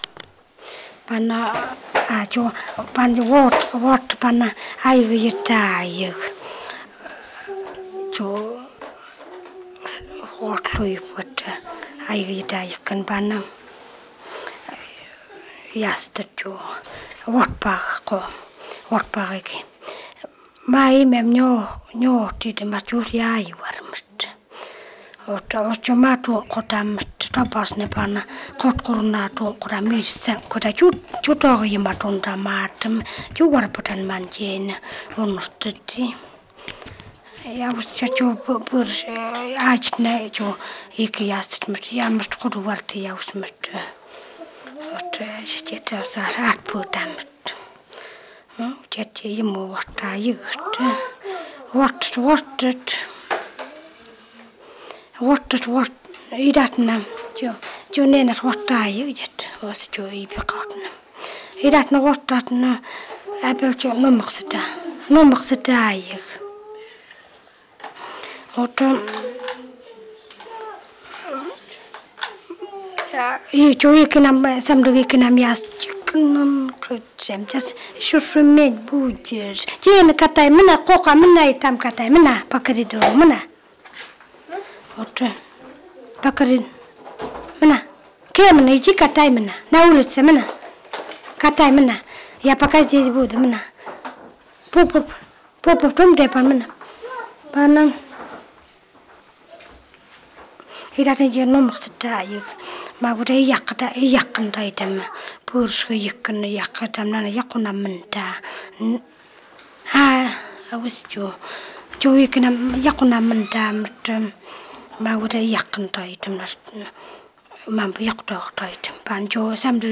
yugan khanty (YK)
Tales (tal)